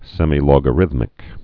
(sĕmē-lôgə-rĭthmĭk, -lŏgə-, sĕmī-)